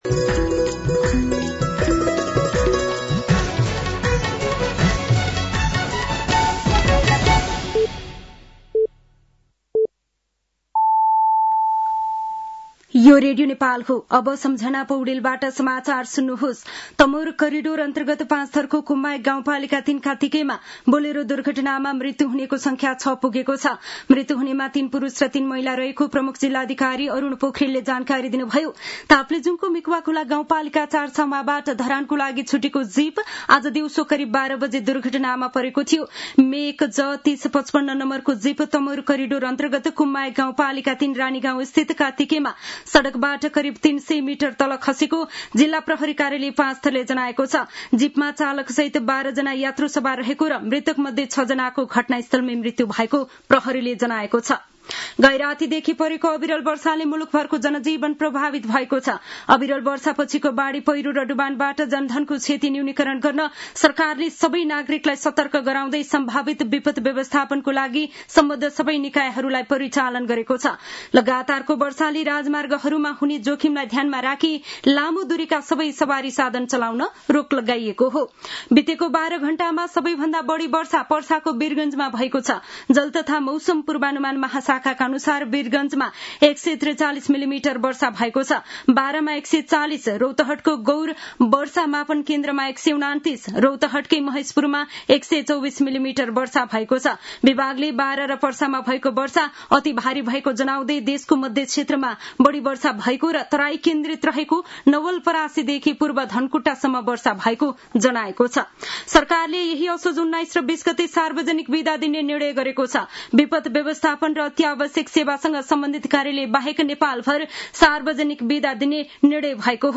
An online outlet of Nepal's national radio broadcaster
साँझ ५ बजेको नेपाली समाचार : १८ असोज , २०८२